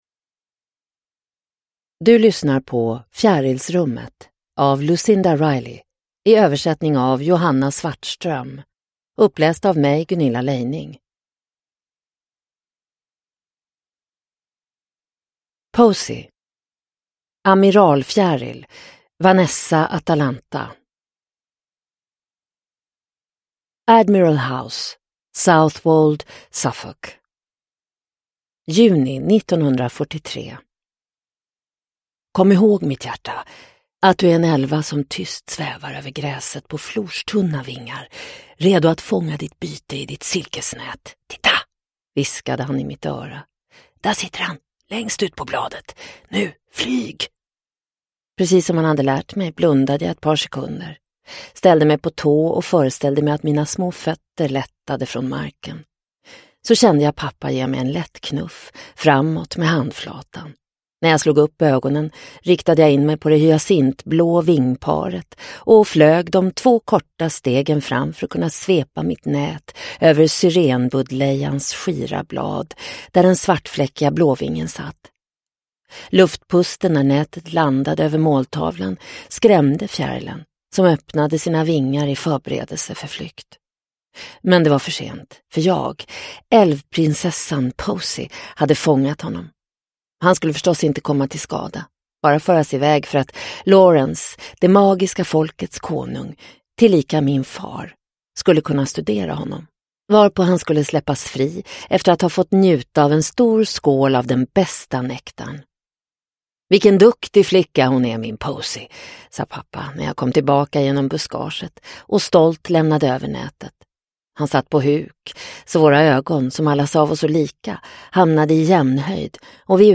Fjärilsrummet – Ljudbok – Laddas ner